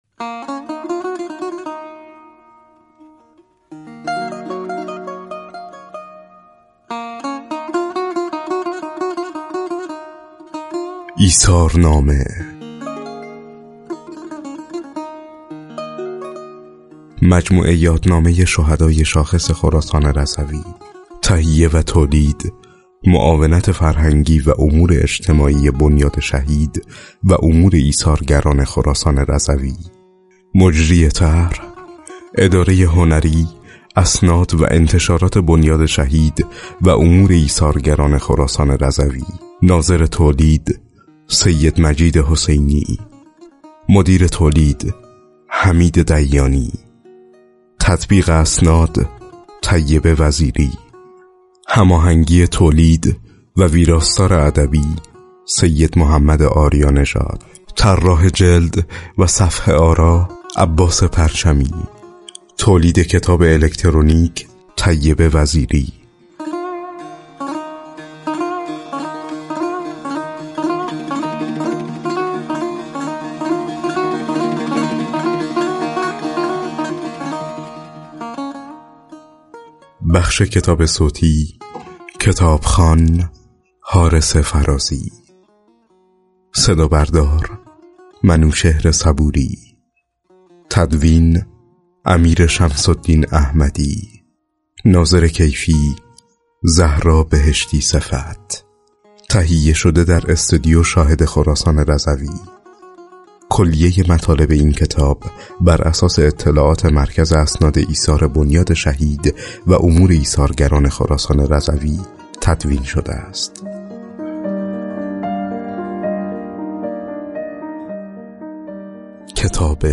بر این اساس کتاب‌های جیبی، الکترونیکی، و کتاب صوتی 72 تن از شهیدان شاخص استان از میان شهیدان انقلاب اسلامی، ترور، دفاع مقدس، مرزبانی، دیپلمات و مدافع حرم منتشر و رونمایی شده است.